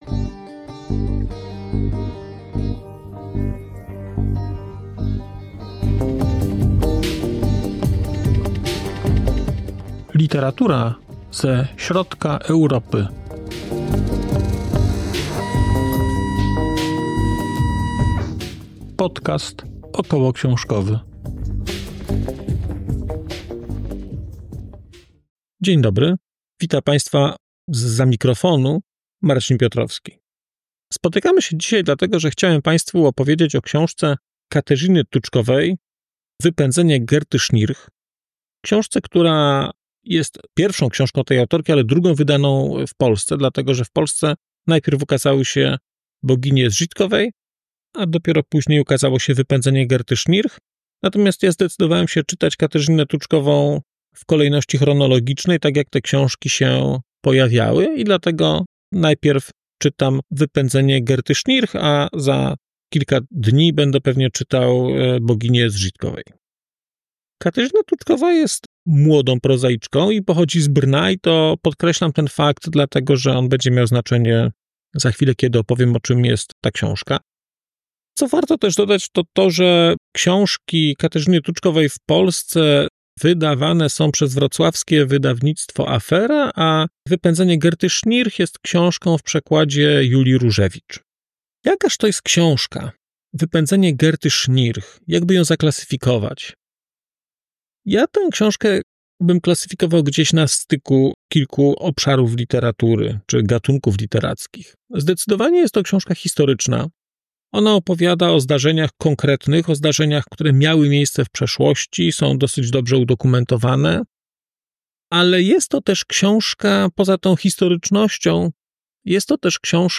🔧 odcinek zremasterowany: 19.03.2025